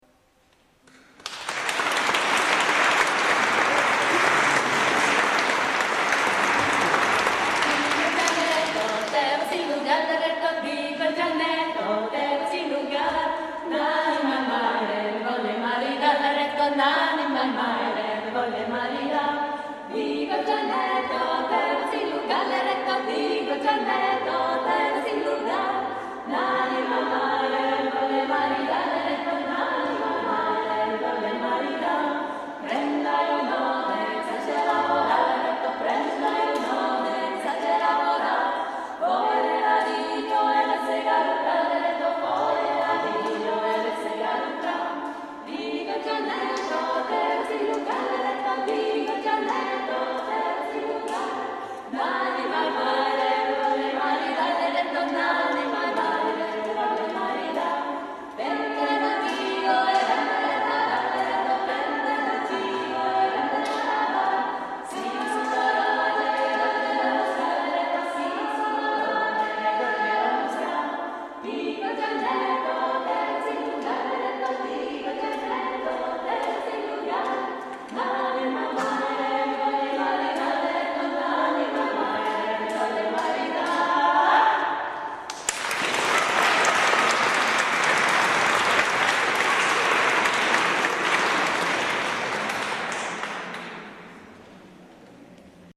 concerts de polyphonies du monde
4 à 10 chanteurs
extraits audio de concerts
enregistrés à Chaudes Aigues, août 06 & à Trets, novembre 06